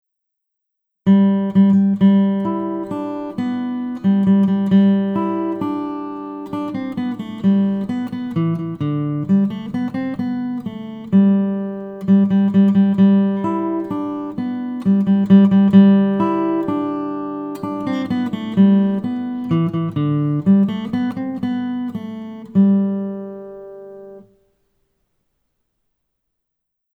DIGITAL SHEET MUSIC - FINGERPICKING SOLO